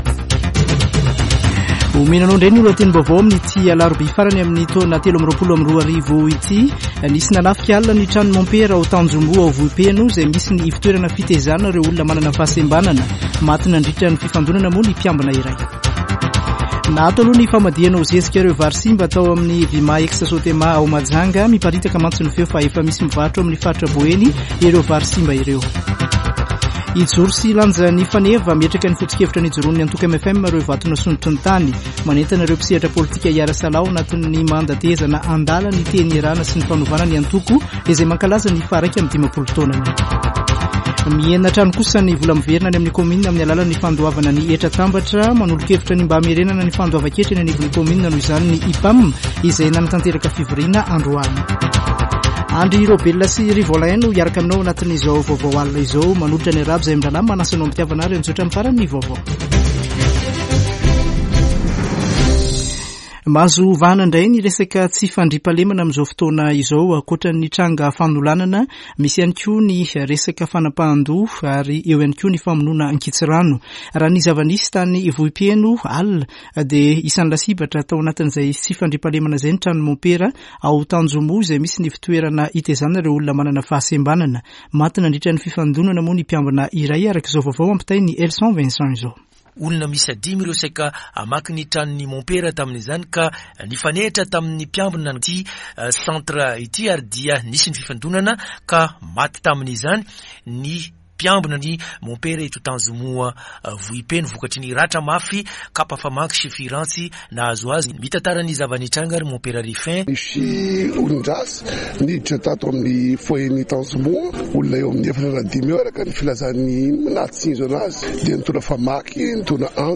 [Vaovao hariva] Alarobia 27 desambra 2023